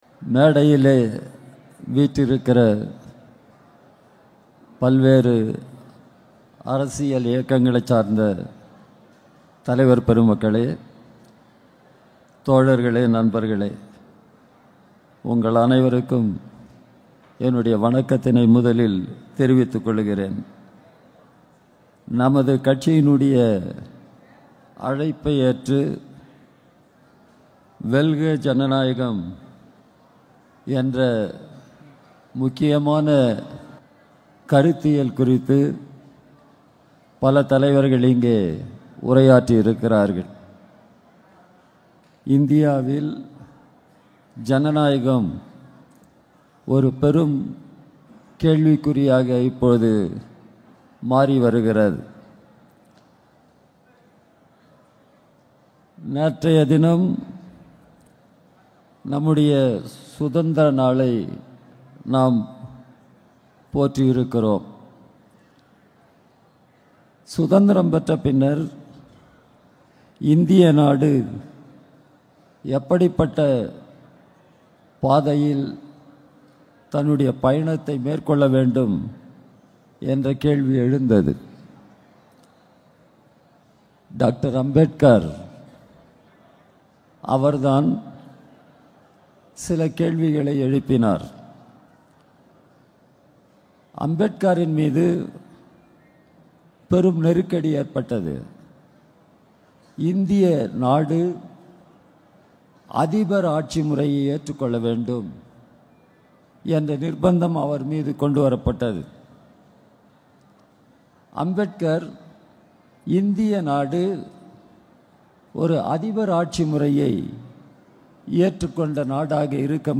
‘‘இன்றைக்கு மோடி அரசாங்கம், நாடாளுமன்றத்தைச் செயலிழக்கச் செய்து, நம்முடைய ஜனநாயகத்தை படுகொலை செய்து, இந்தியாவில் ஒரு சர்வாதிகார ஆட்சியை நிலைநிறுத்த வேண்டும் என்று விரும்புகிறது.’’ என்று இந்தியக் கம்யூனிஸ்ட் கட்சியின் பொதுச் செயலாளர் டி.ராஜா சேலம் மாநாட்டில் உரை நிகழ்த்தினார்.